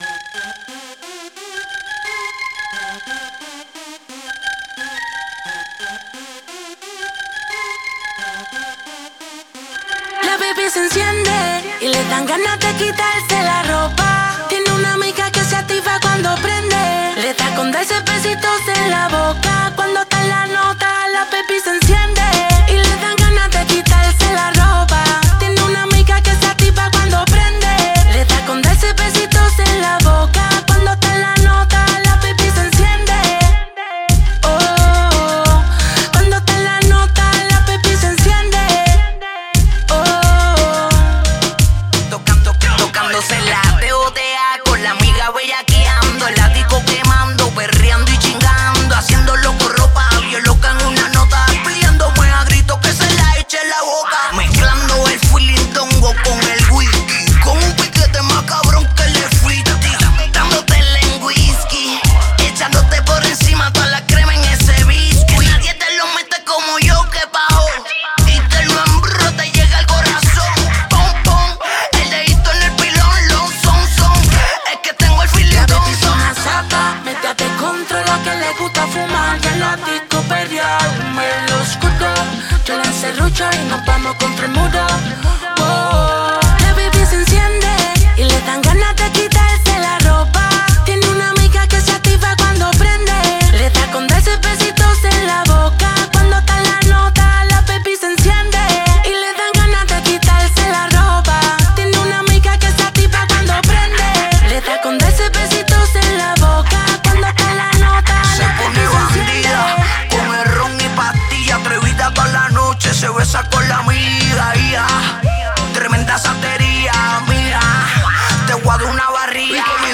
Genre: Punjabi